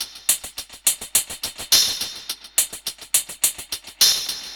Index of /musicradar/dub-drums-samples/105bpm
Db_DrumKitC_Wet_EchoHats_105-02.wav